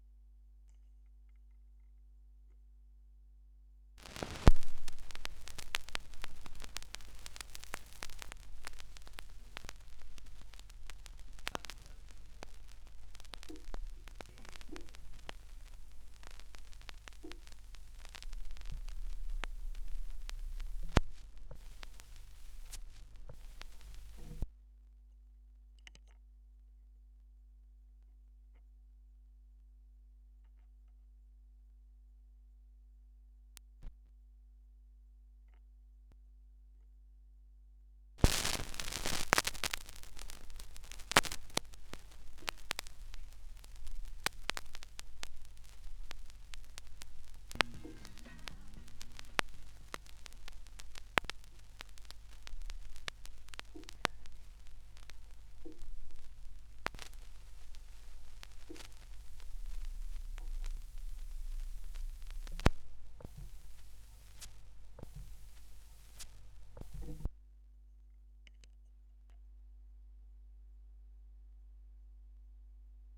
2017 Schallplattengeräusche (3).m3u